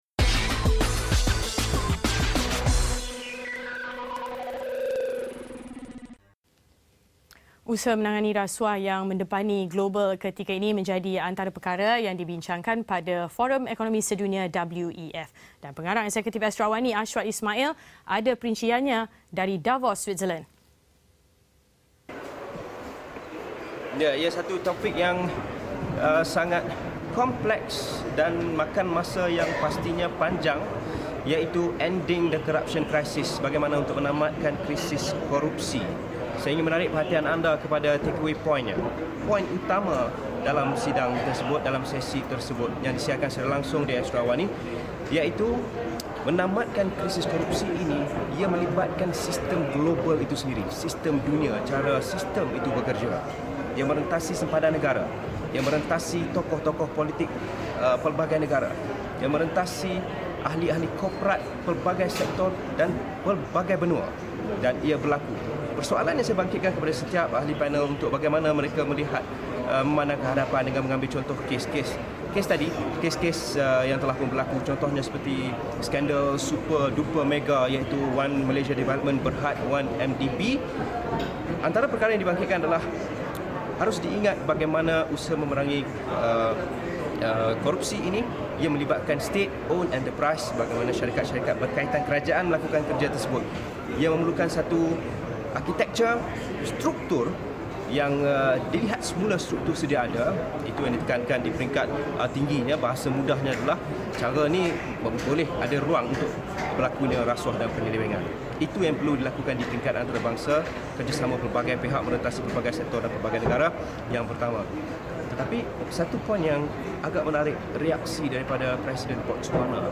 ada perinciannya dari Davos Switzerland.